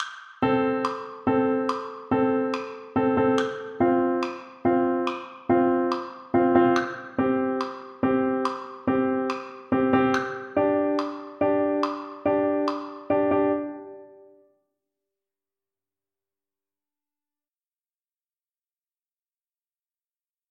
para flauta, y xilófonos.